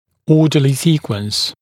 [‘ɔːdəlɪ ‘siːkwəns][‘о:дэли ‘си:куэнс]упорядоченная последовательность, правильная последовательность